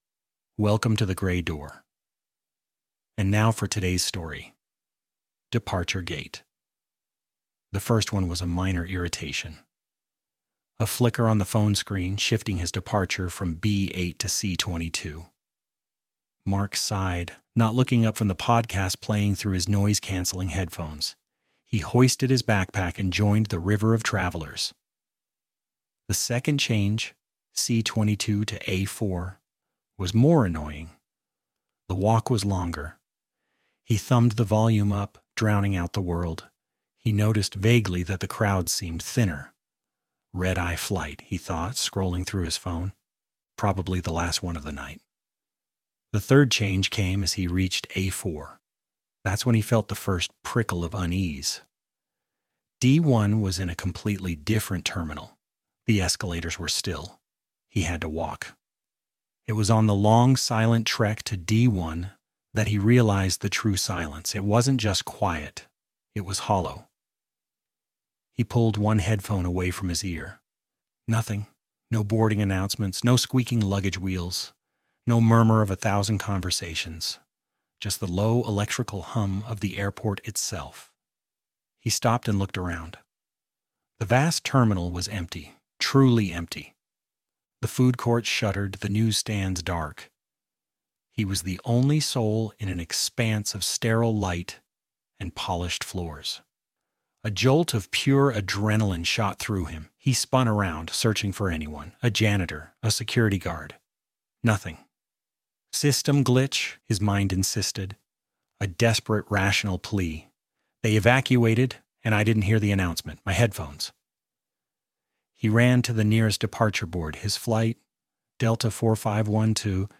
Category: Fiction